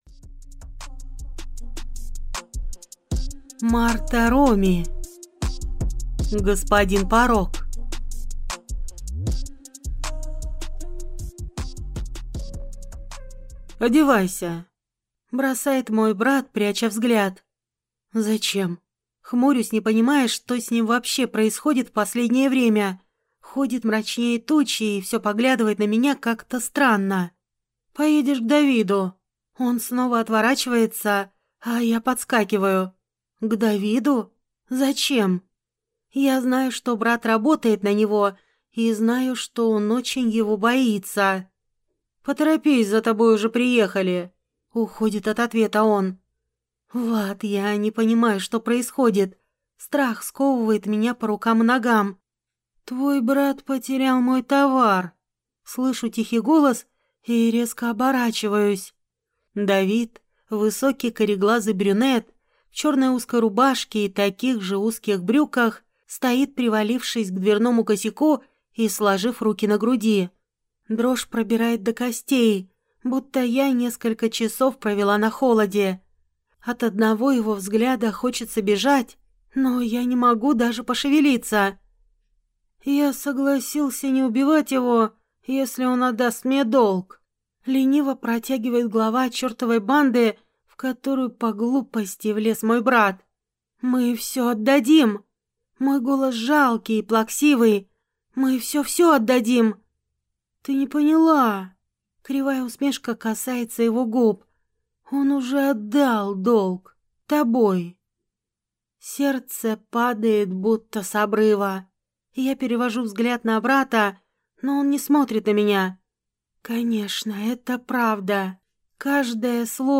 Аудиокнига Господин Порок | Библиотека аудиокниг